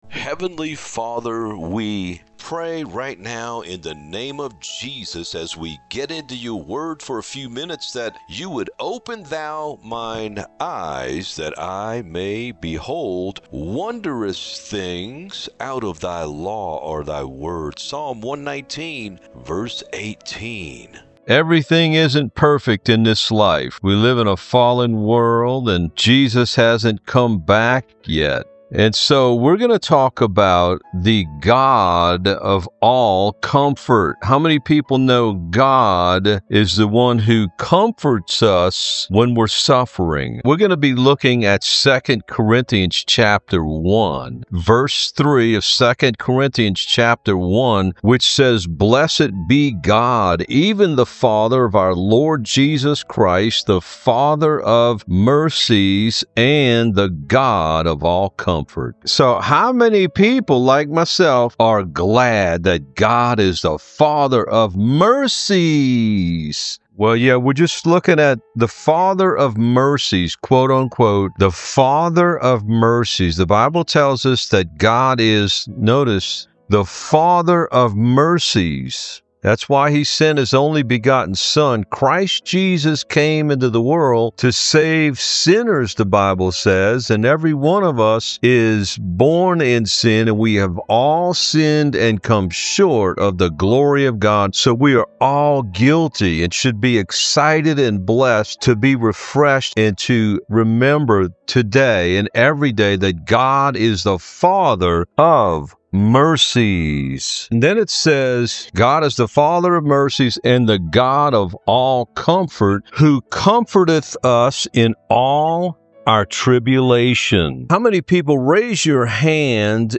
The-God-of-all-Comfort-EDITED-MUSIC.mp3